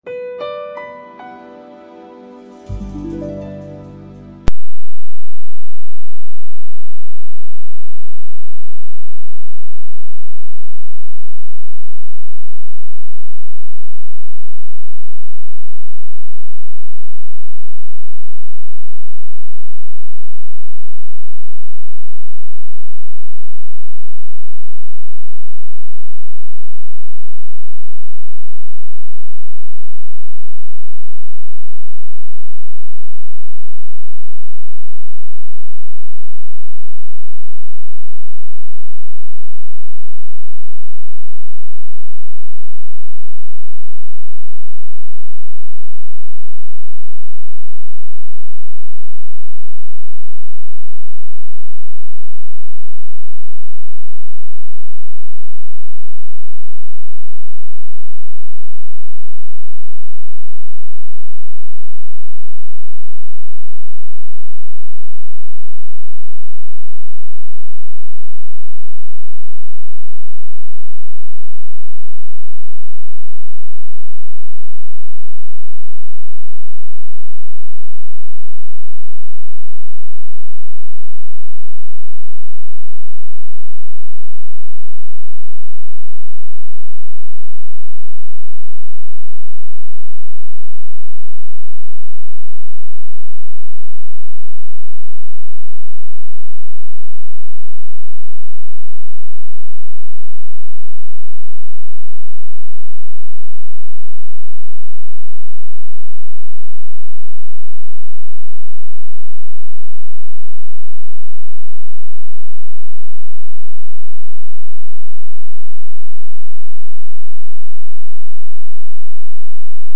prueba de grabación